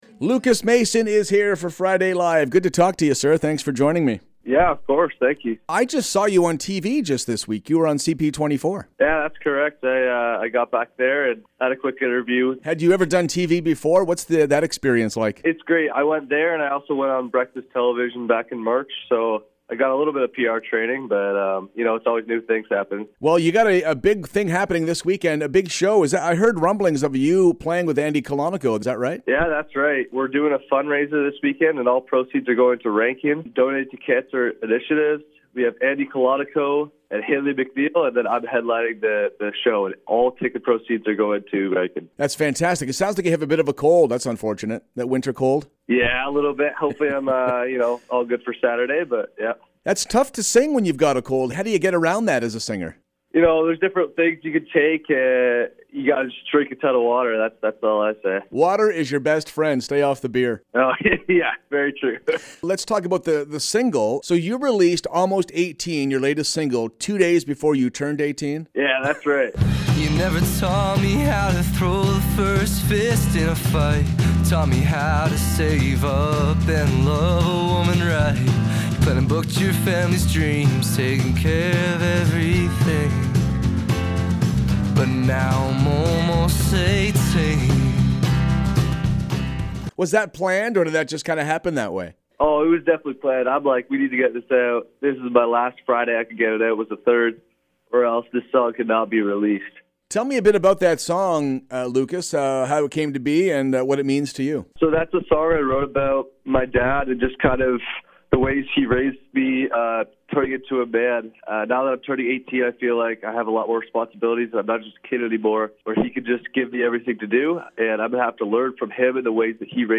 Tune in every Friday morning for weekly interviews, performances, everything LIVE!